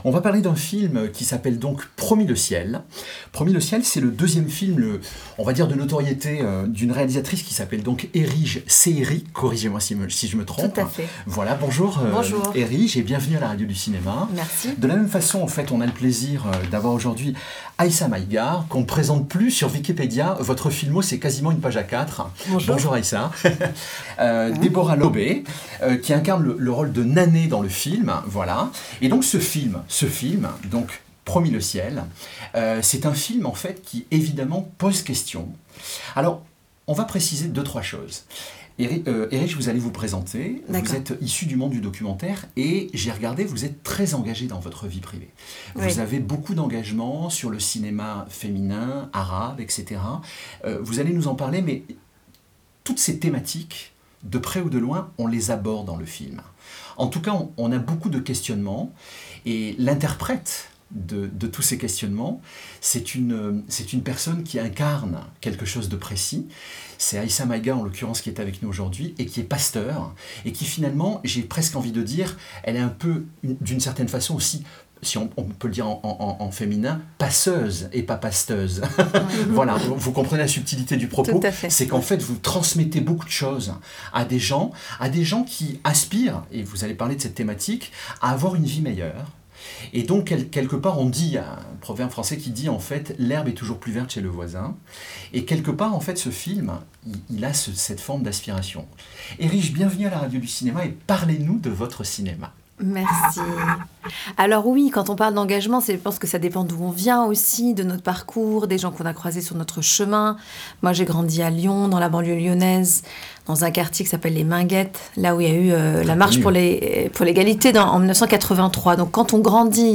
Dans l’interview, Aïssa Maïga insiste sur la nature “très chorale” du film : Marie a un rôle pivot parce qu’elle “organise la solidarité”.